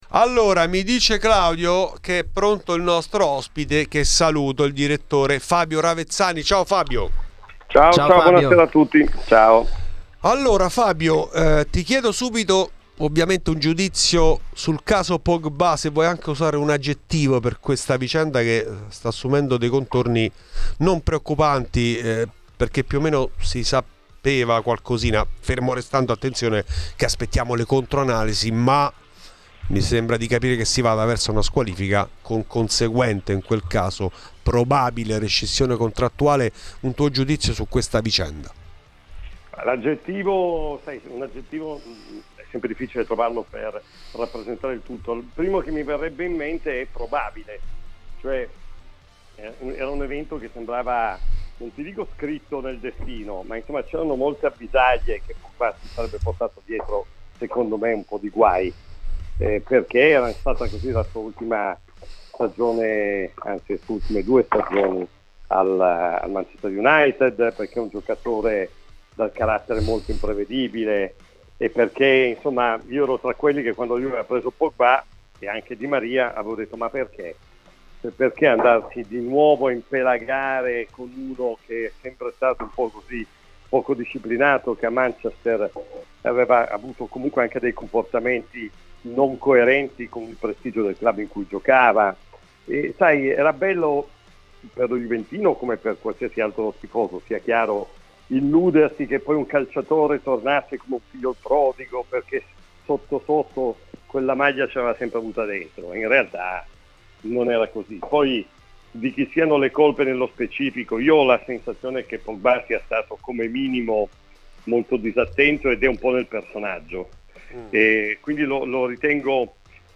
Nel podcast l'intervento integrale.